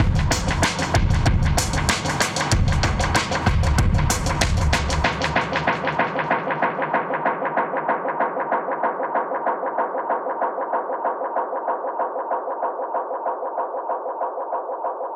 Index of /musicradar/dub-designer-samples/95bpm/Beats
DD_BeatFXA_95-01.wav